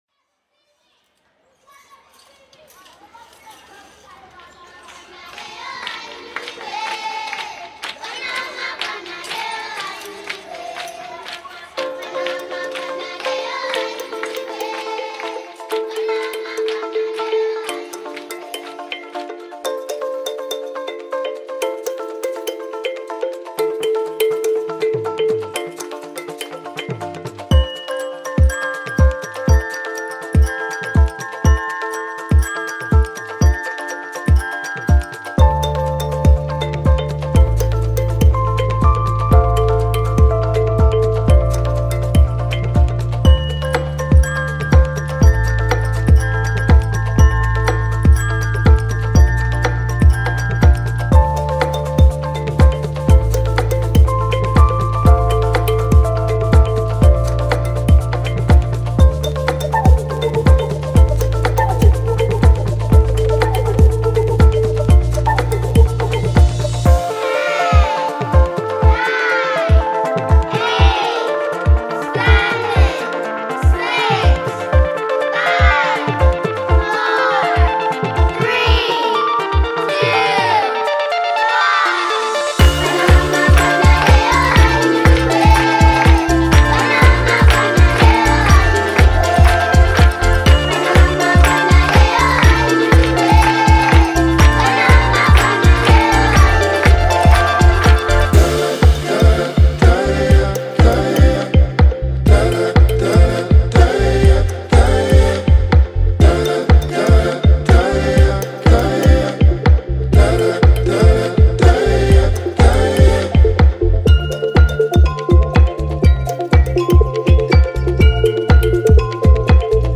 German house duo